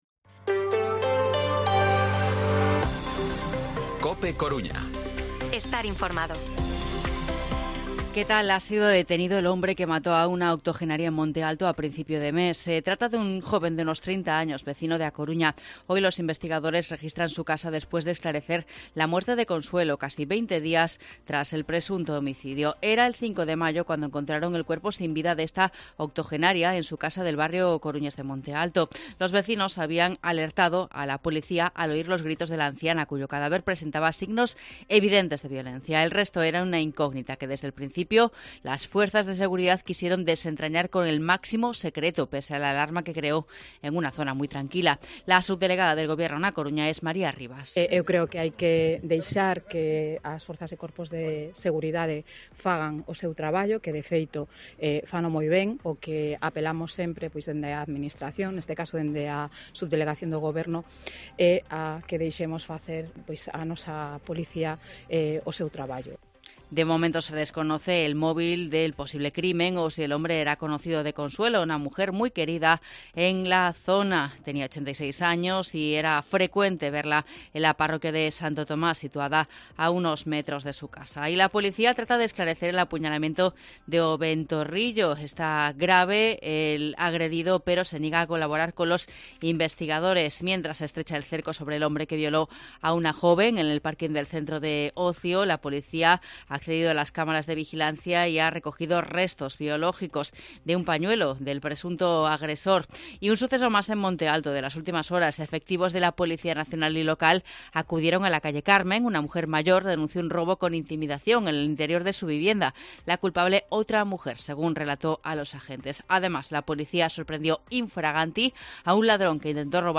Informativo Mediodía COPE Coruña martes, 23 de mayo de 2023 14:20-14:30